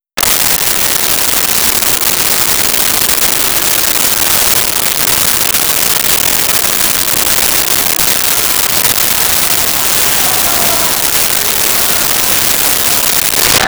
Crowd Boo Medium 02
Crowd Boo Medium 02.wav